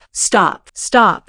audio_stopstop.wav